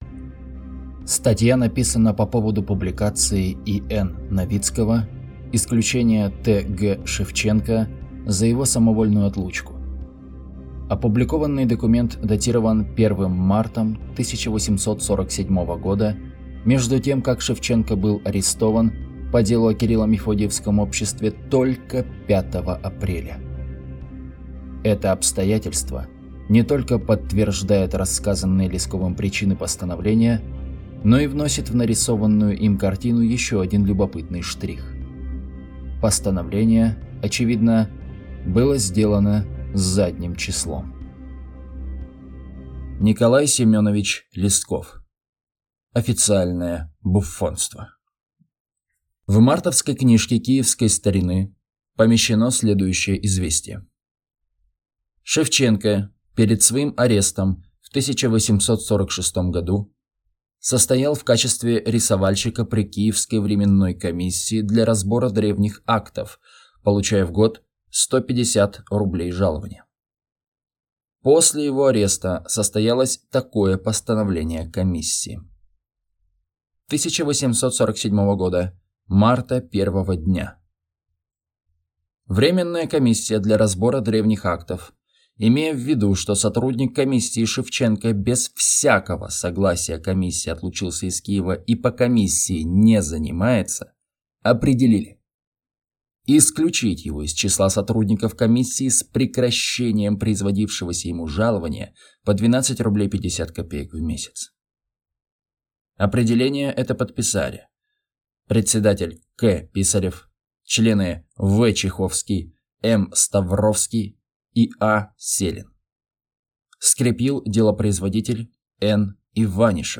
Аудиокнига Официальное буффонство | Библиотека аудиокниг